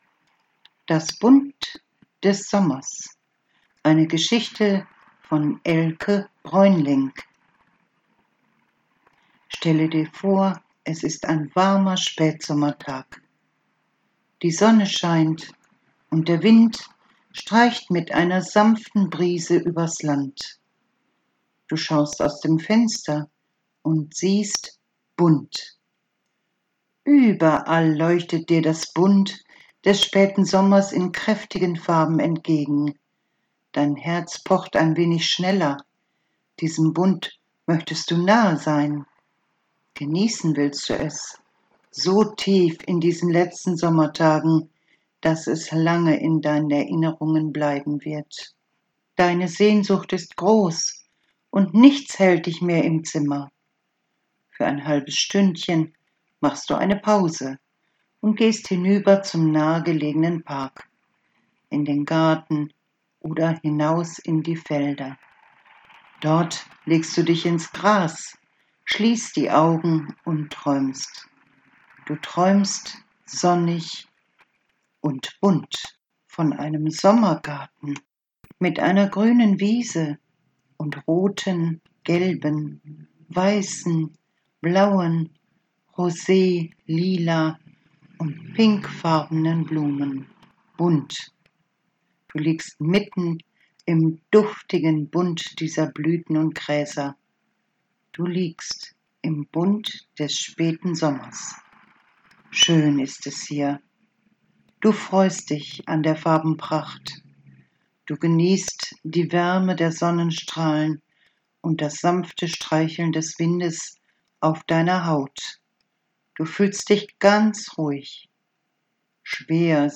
Kleine Fantasiereise – Du schließt die Augen und träumst die Farben des Sommers